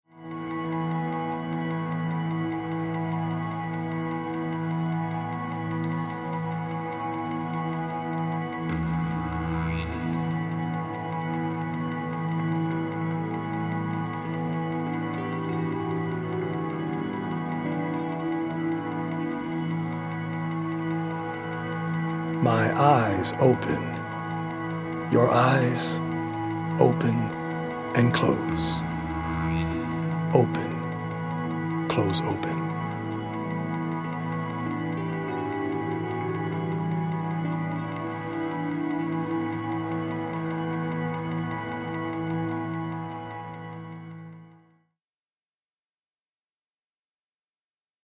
poetic
healing Solfeggio frequency music